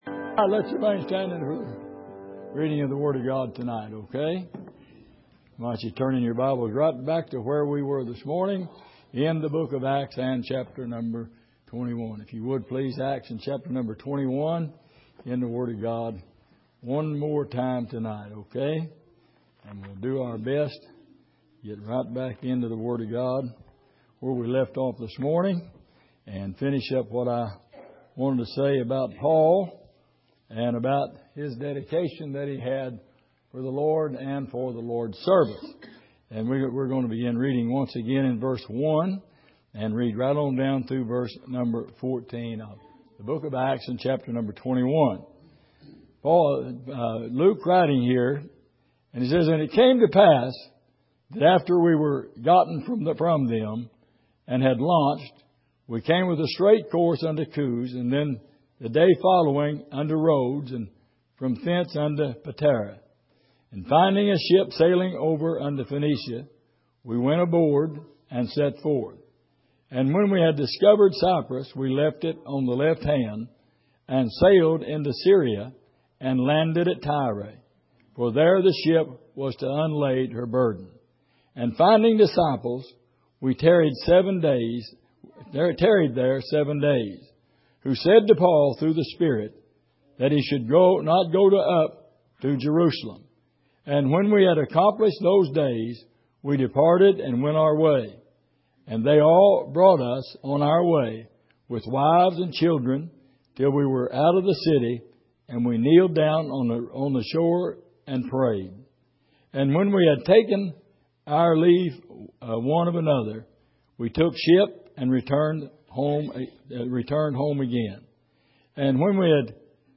Passage: Acts 21:1-14 Service: Sunday Evening